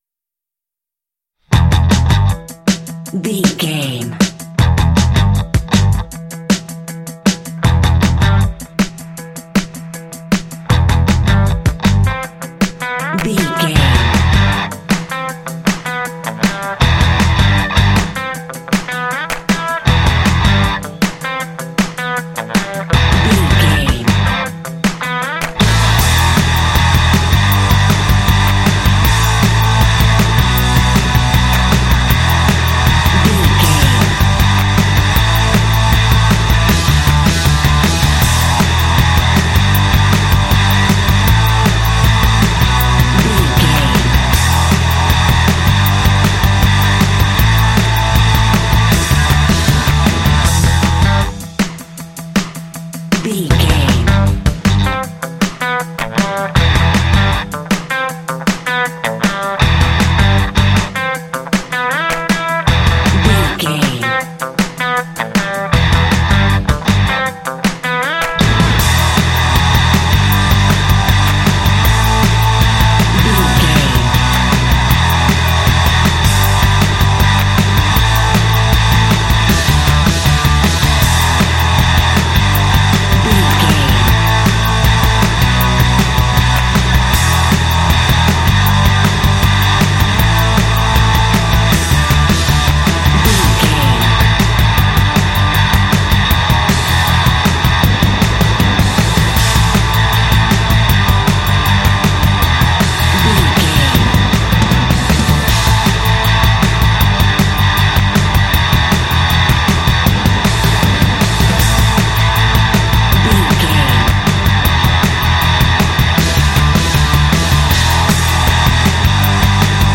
Aeolian/Minor
Fast
driving
intense
powerful
energetic
bass guitar
electric guitar
drums
heavy metal
symphonic rock